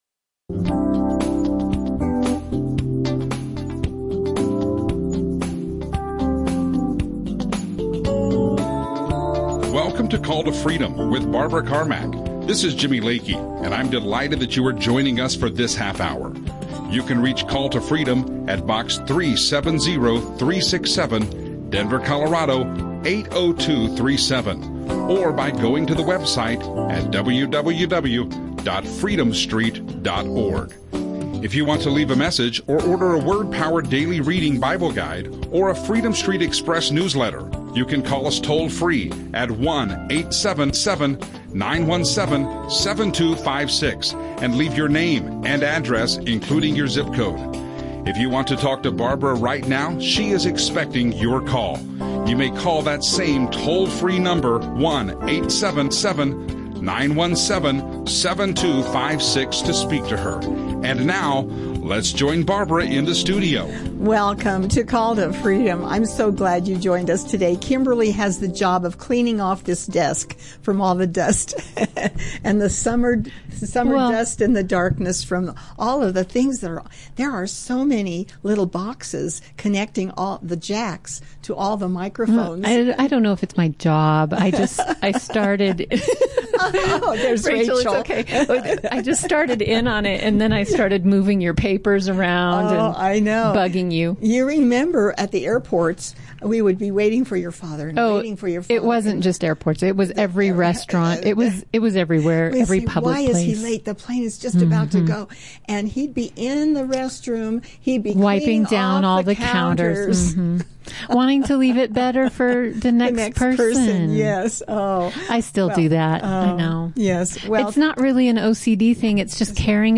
Christian radio
live radio show